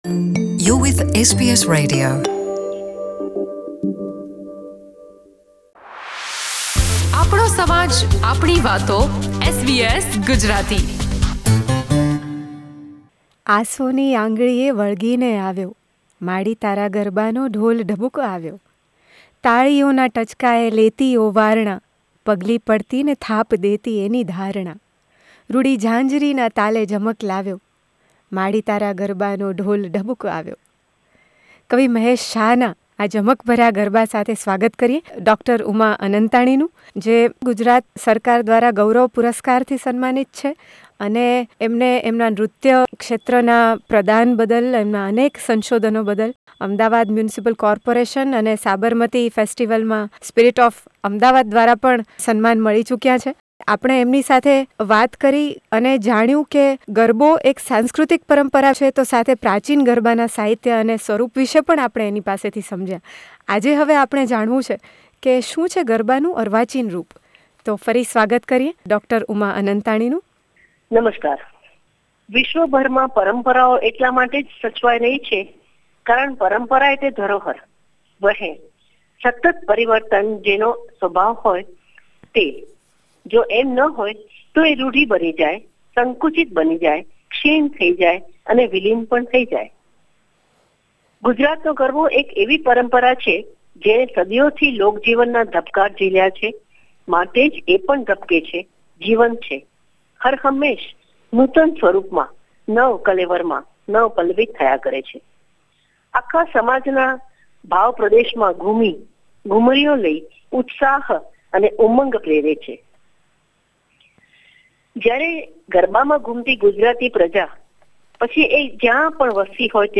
ભારતીય નૃત્યને વિશ્વસ્તરે લઇ જનાર ગુજરાતનાં આ નૃત્યવિદ્દ SBS Gujarati સાથેની વાતચીતમાં સ-રસ વિગતે સમજાવે છે અર્વાચીન ગરબા વિષે.